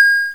win.wav